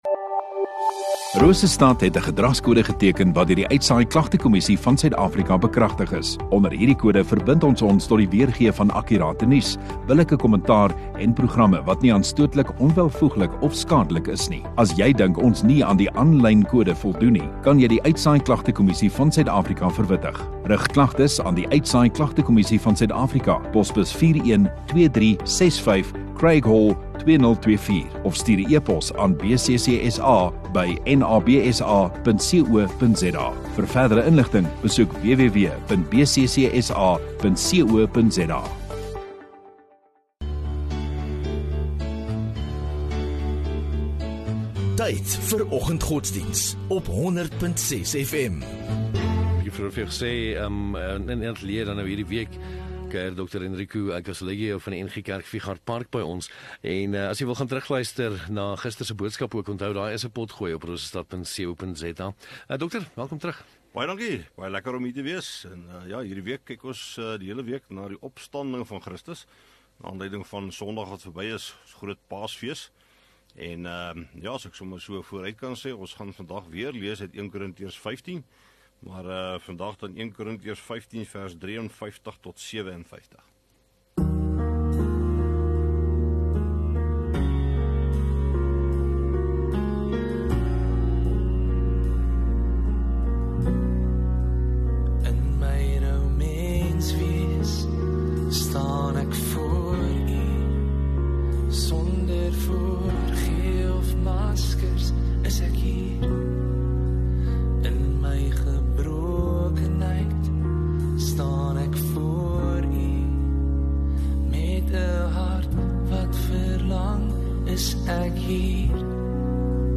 2 Apr Dinsdag Oggenddiens